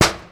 Percussive FX 09 ZG